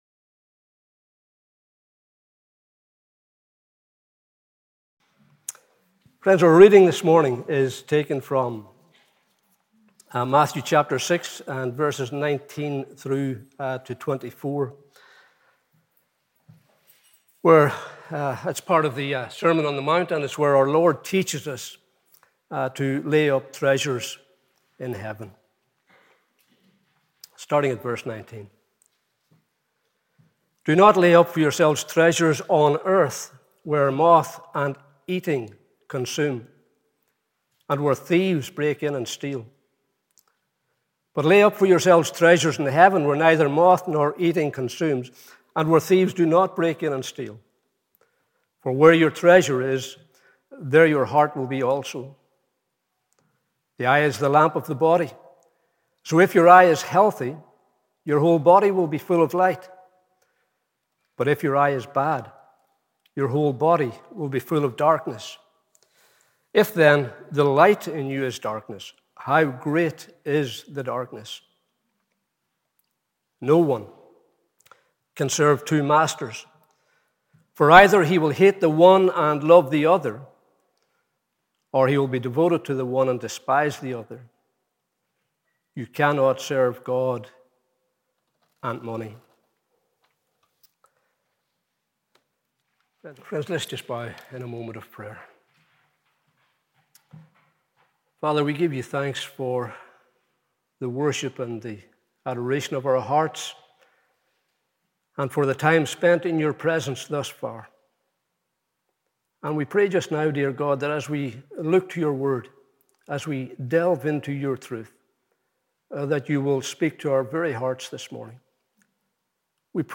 Morning Service 25th July 2021